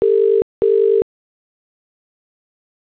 ringback_uk.wav